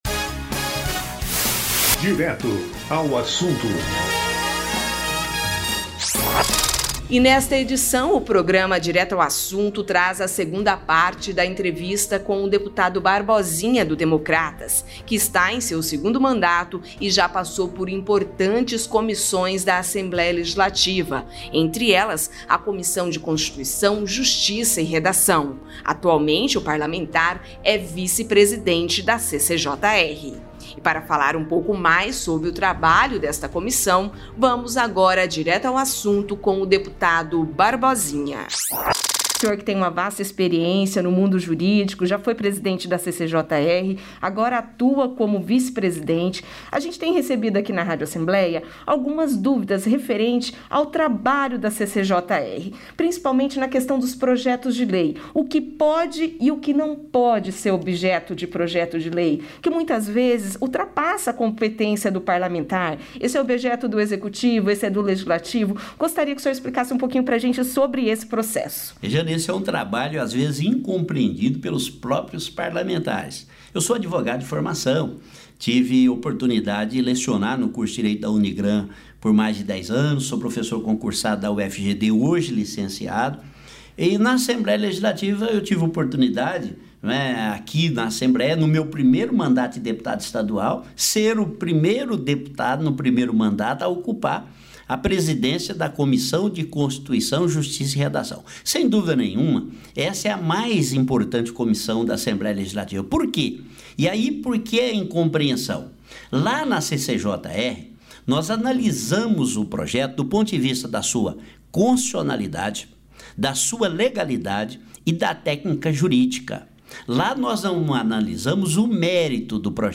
Nesta edição o programa Direto Assunto traz a segunda parte da entrevista com o deputado Barbosinha (DEM), que está em seu segundo mandato, e já passou por importantes comissões da Assembleia Legislativa, entre elas a Comissão de Constituição Justiça e Redação, atualmente o parlamentar é vice-presidente da CCJR. E para falar um pouco mais sobre o trabalho da comissão, o deputado Barbosinha explicou durante o programa a importância desta comissão.